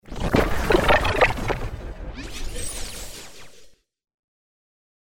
PLAY alerts efect
alerts-efect.mp3